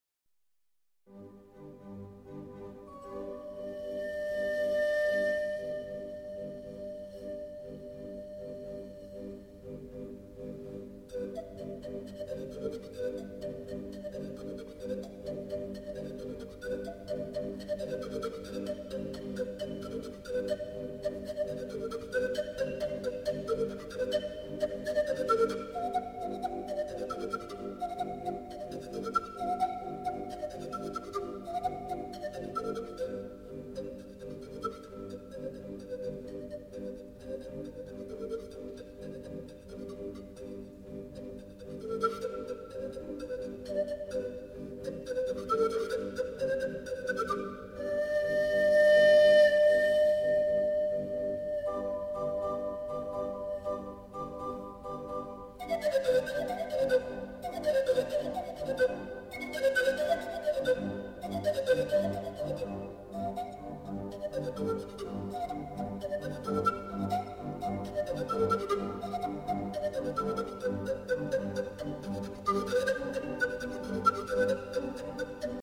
La dinamica risultante è spettacolare!